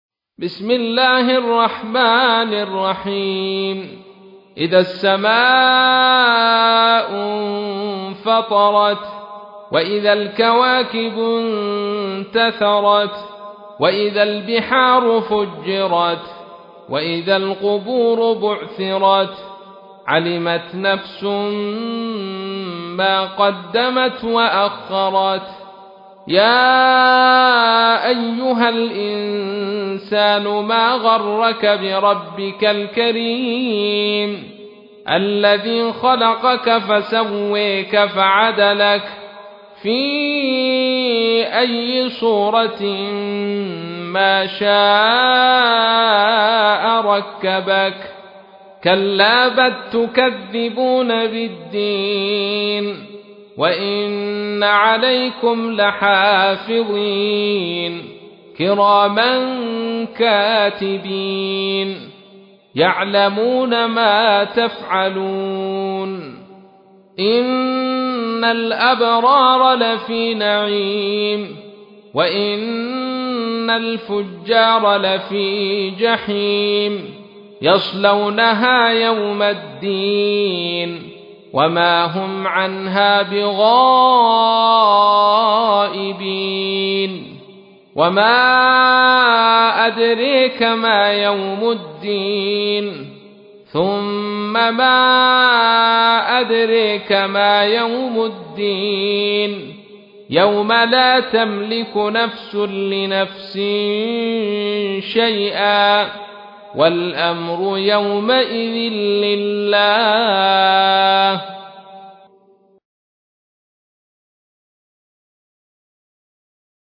تحميل : 82. سورة الانفطار / القارئ عبد الرشيد صوفي / القرآن الكريم / موقع يا حسين